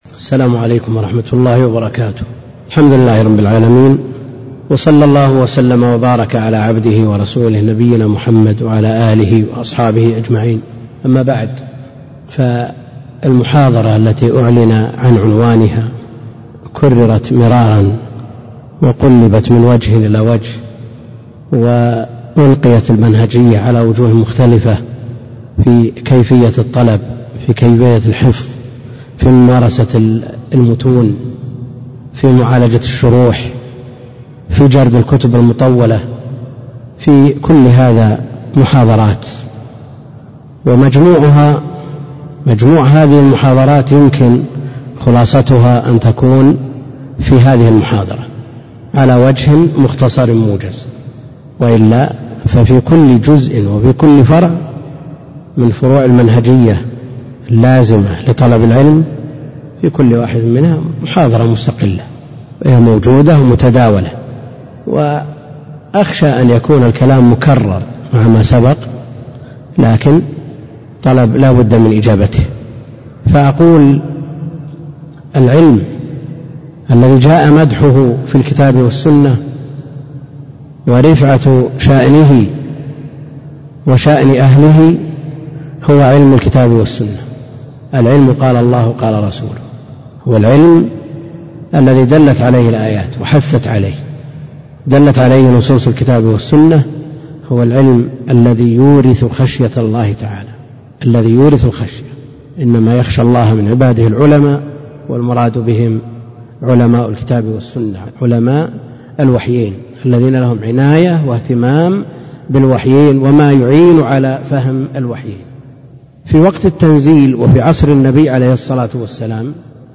محاضرة صوتية نافعة، وفيها بيَّن الشيخ عبد الكريم الخضي